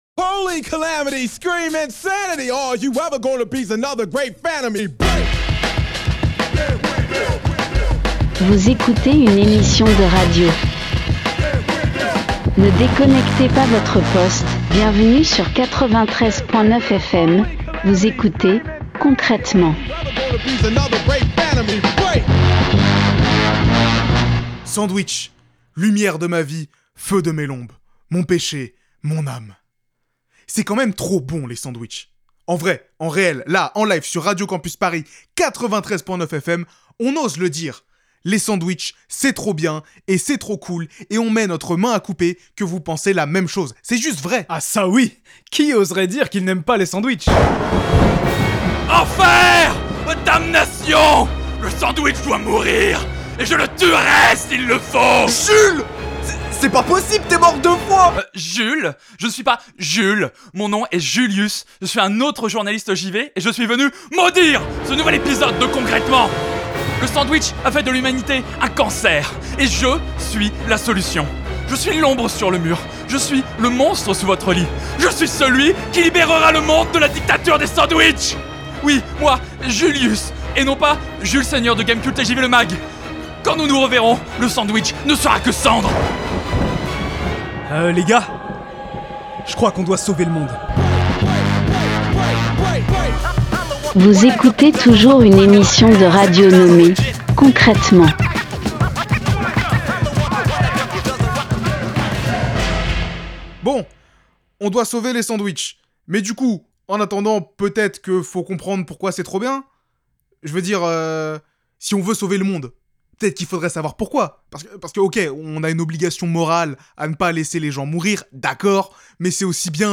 Concrètement : Est-ce que le sandwich est un repas parfait ? Partager Type Création sonore Société vendredi 2 mai 2025 Lire Pause Télécharger L'heure est grave.